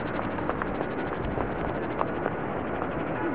sidewalk.au